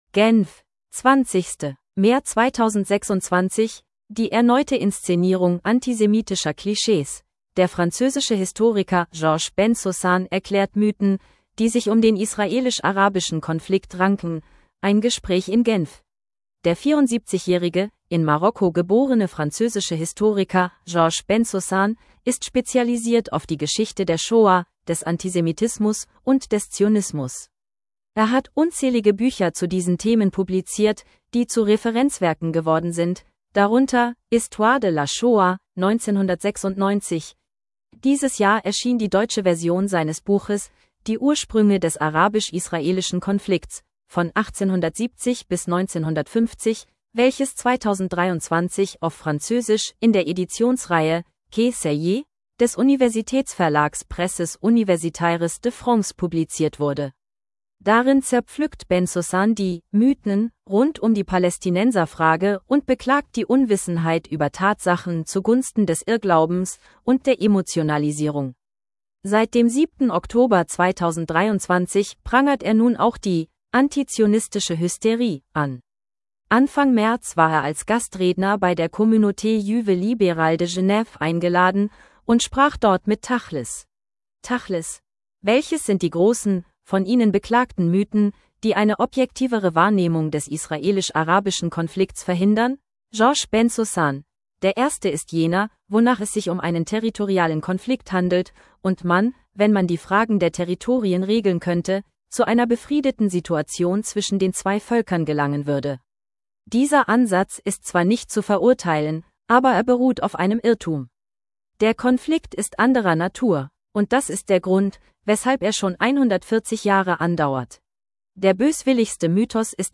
Der französische Historiker Georges Bensoussan erklärt Mythen, die sich um den israelisch-arabischen Konflikt ranken – ein Gespräch in Genf.